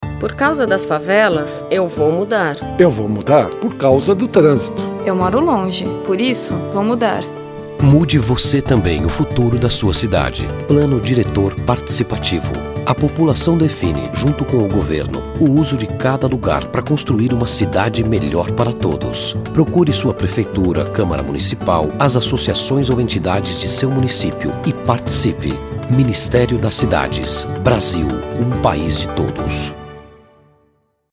Spots de radio